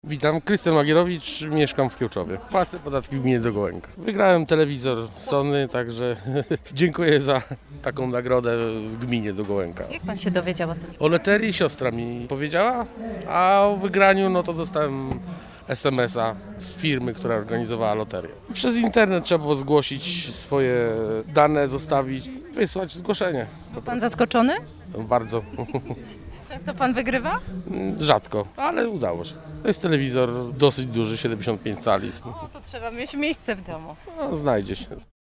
W Parku w Szczodrem odbył się piknik, dopisały frekwencja oraz pogoda.